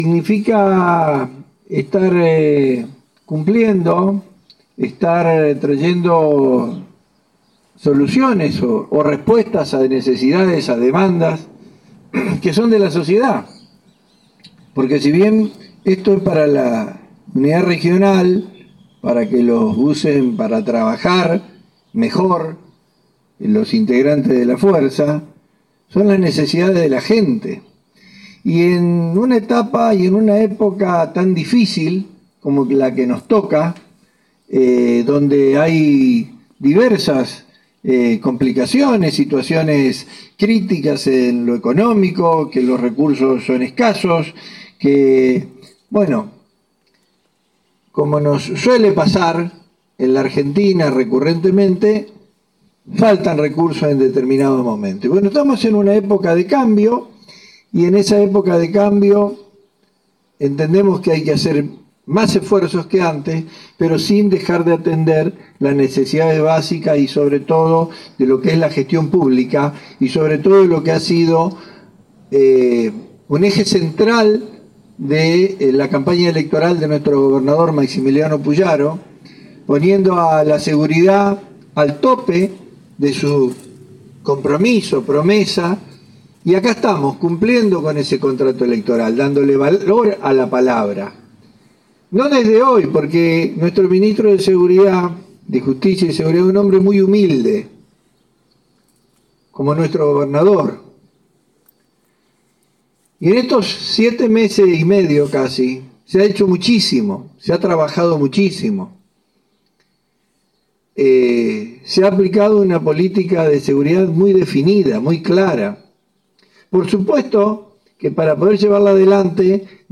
Fue en un acto realizado en la U.R XIX que fue encabezado por los ministros, Pablo Cococcioni y Fabián Bastia.
Fabián Bastia – ministro de Gobierno en Innovación Pública